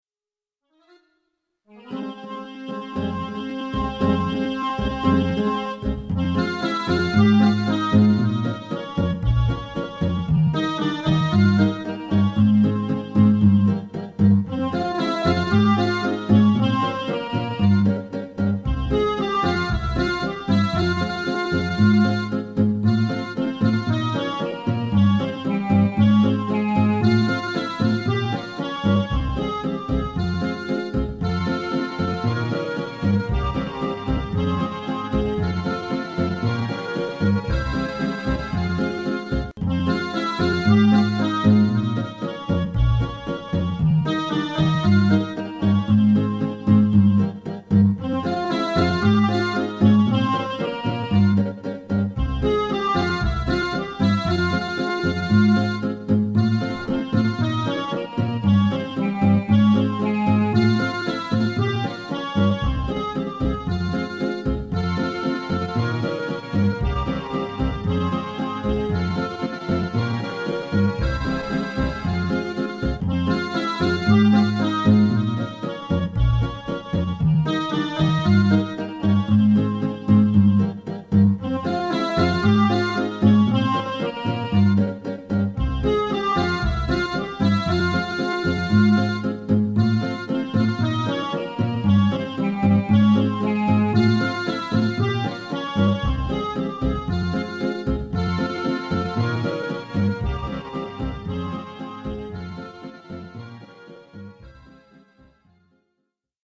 сейчас сделаю под 3 куплета.если нужно.